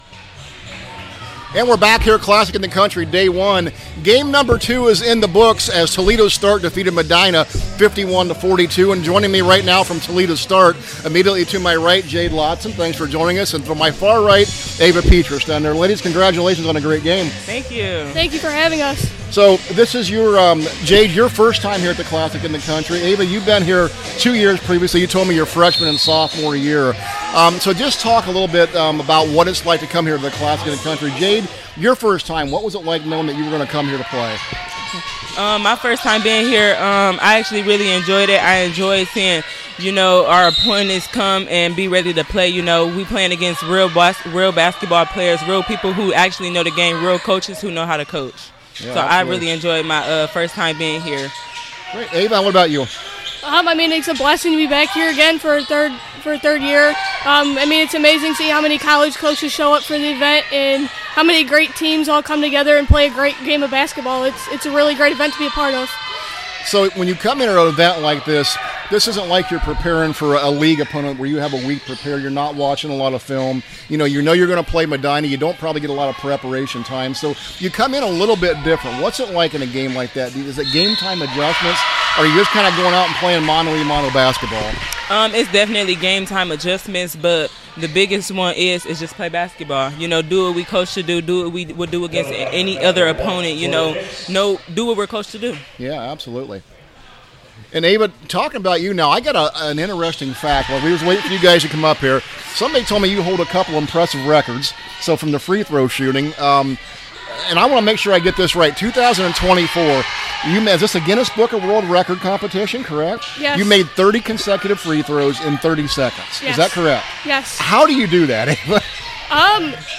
2026 CLASSIC – TOLEDO START PLAYER INTERVIEWS